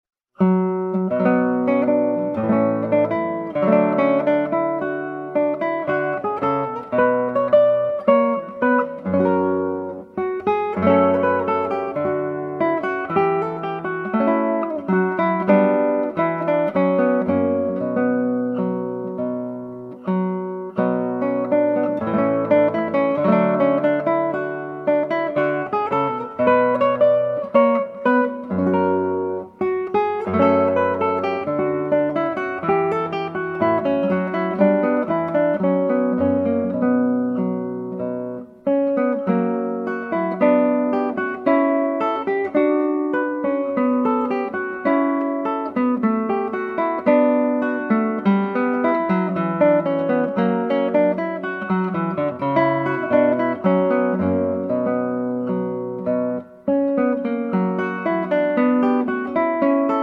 Guitar